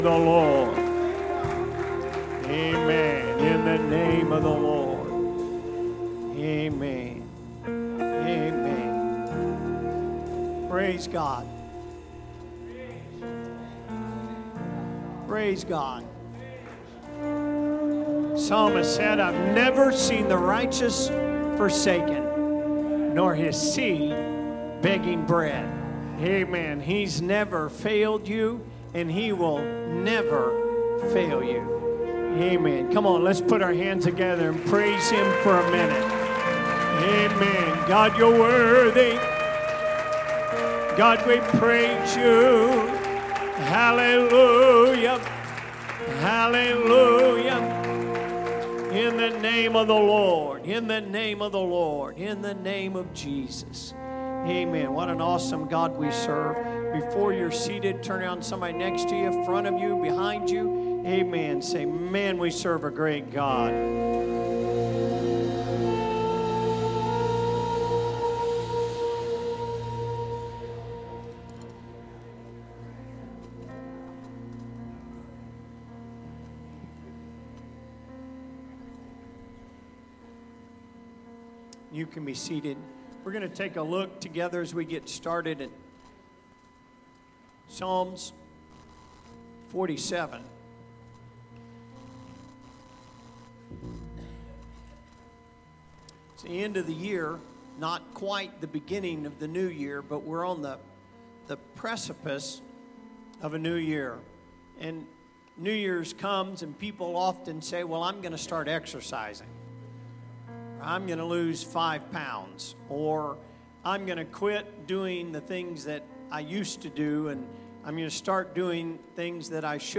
Sunday Service -Enjoying God